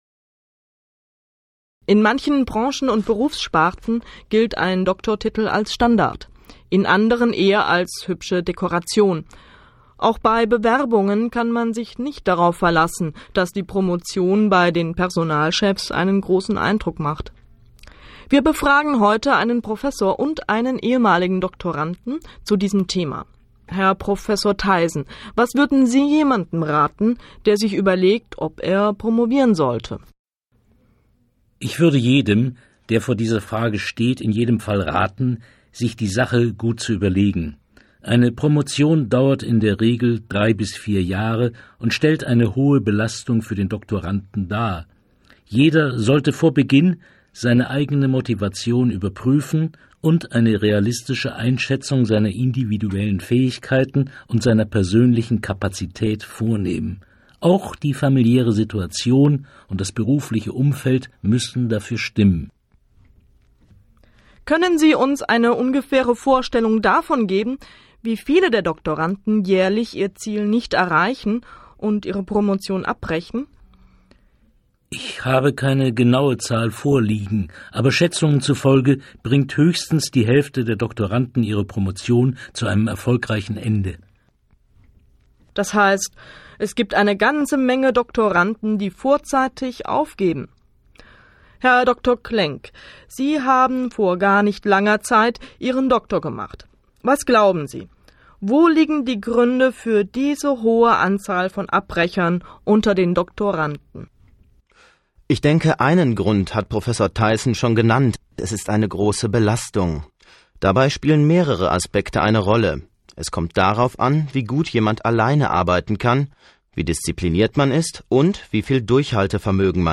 Interview als MP3-Audio Transkript des Interviews
Hörverstehen Modellsatz 02 Hörverstehen Hörtext 2: Aufgaben 9-18 Sie hören ein Interview mit drei Gesprächsteilnehmern über Berufsaussichten von Doktoranden.